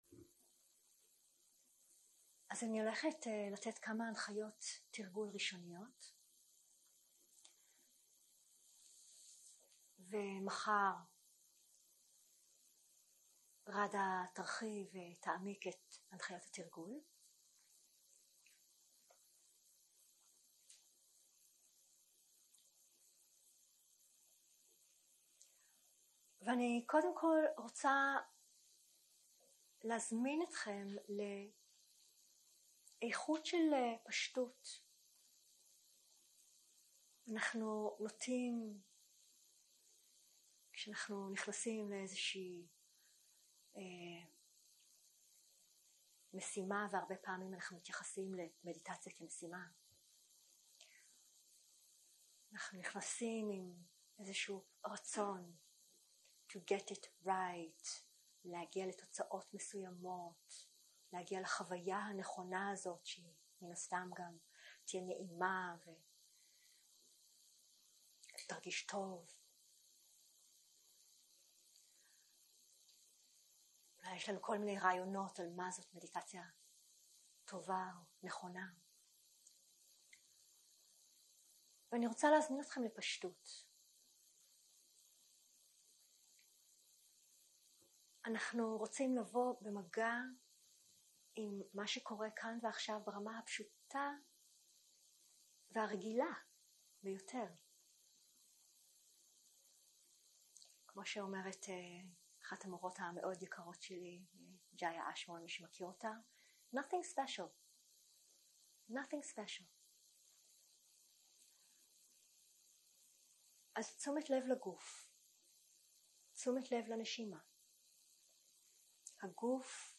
הקלטה 1 - יום 1 - ערב - הנחיות למדיטציה - הנחיות לנשימה והליכה 0:00 0:00 סוג ההקלטה: Dharma type: Guided meditation שפת ההקלטה: Dharma talk language: Hebrew